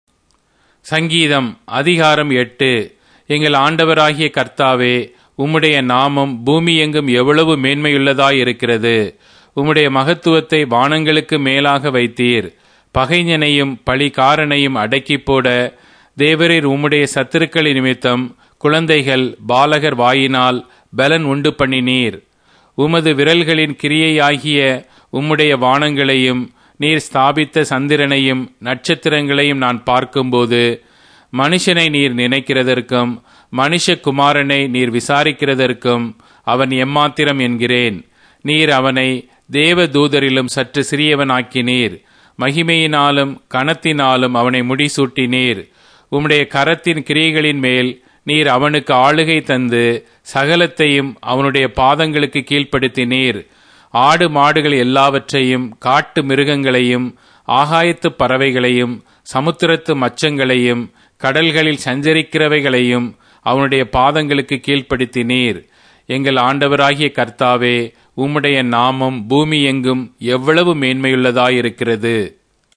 Tamil Audio Bible - Psalms 90 in Alep bible version